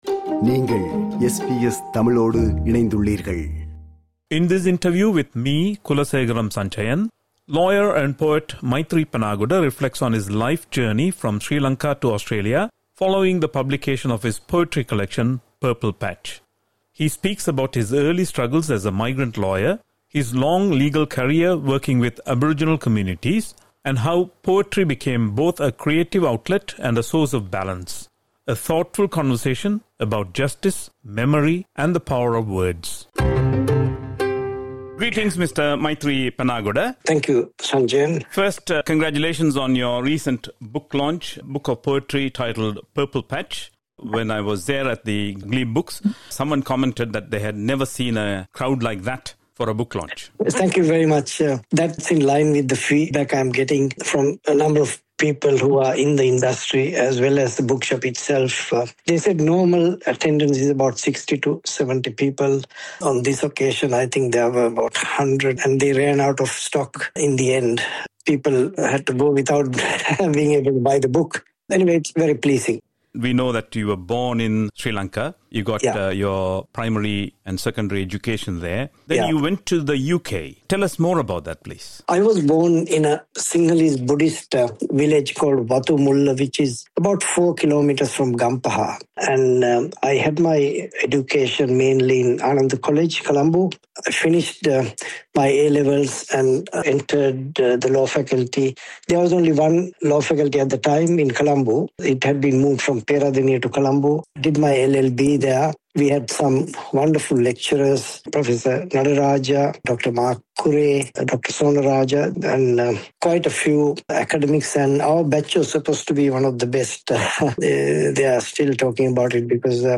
He speaks about his early struggles as a migrant lawyer, his long legal career working with Aboriginal communities, and how poetry became both a creative outlet and a source of balance. A thoughtful conversation about justice, memory, and the power of words.